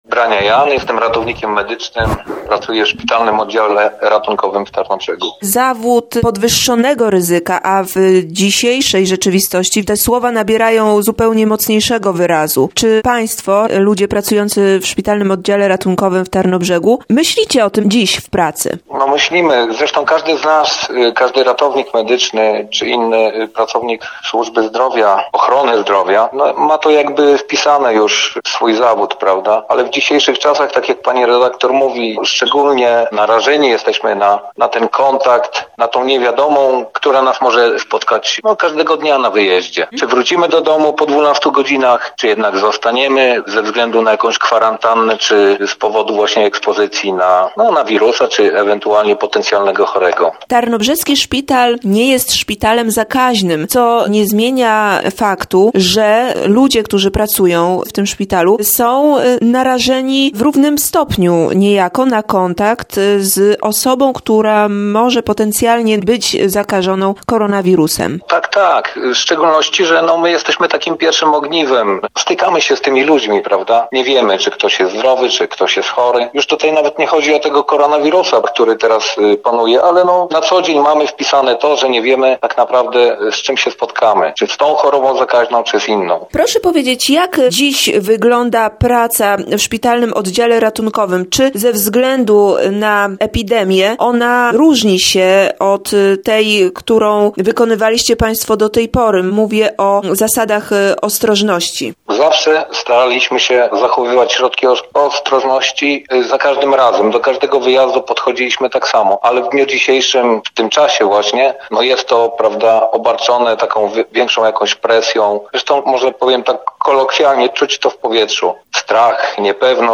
Rozmowa z ratownikiem medycznym SOR Tarnobrzeg.